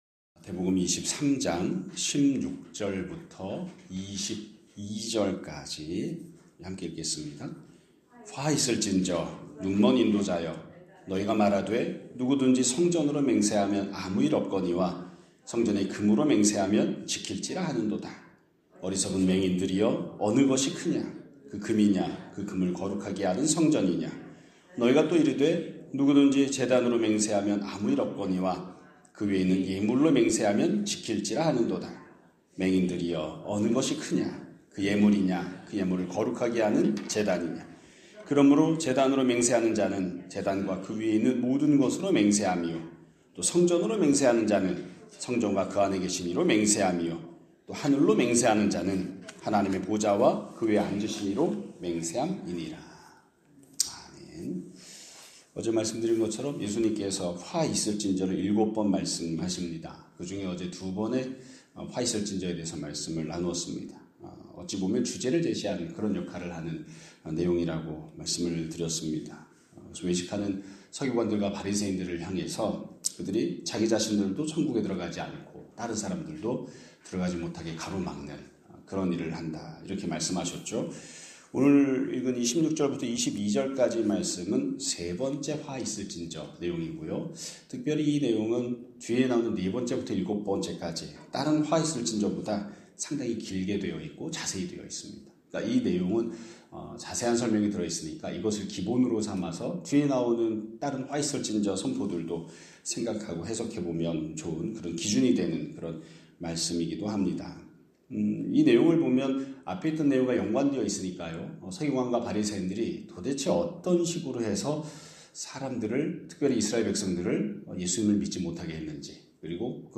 2026년 2월 24일 (화요일) <아침예배> 설교입니다.